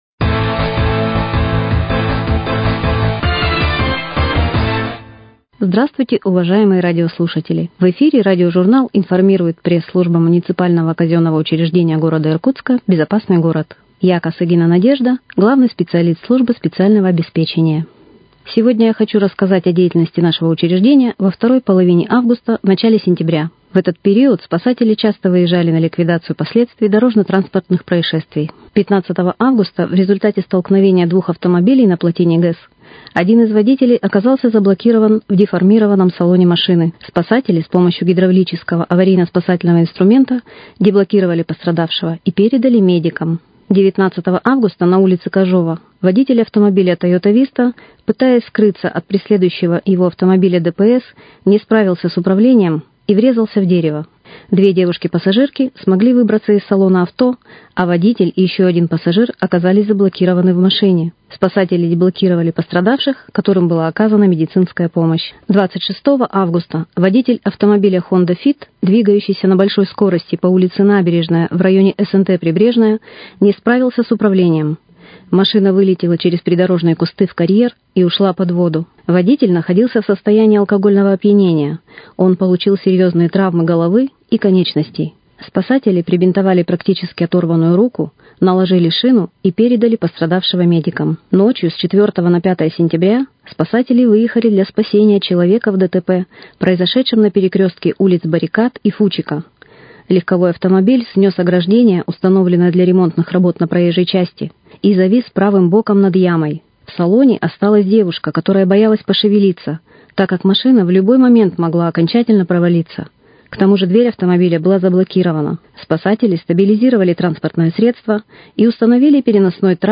Радиожурнал «Информирует МКУ «Безопасный город»: О работе службы эксплуатации светофорных объектов